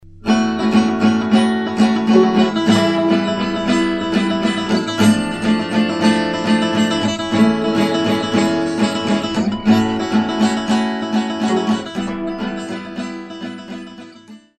drunk country style